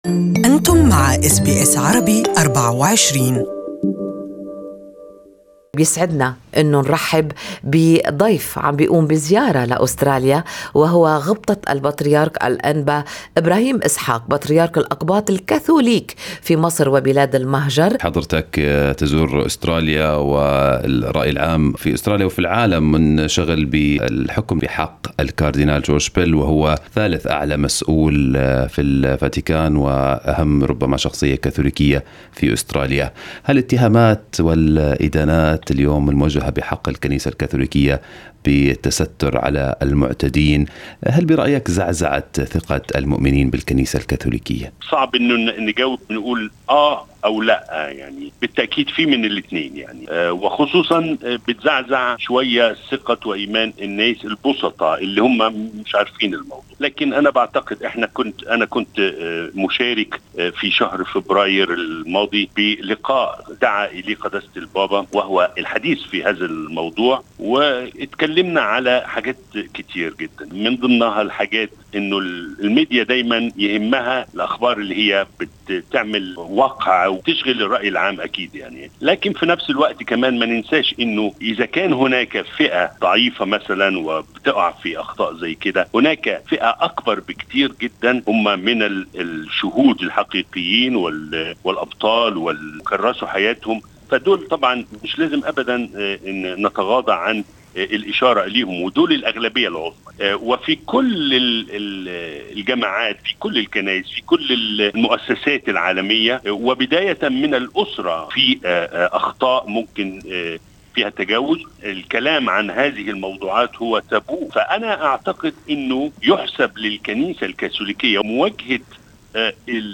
استمعوا إلى المقابلة مع البطريرك ابراهيم اسحق في التسجيل المرفق بالصورة.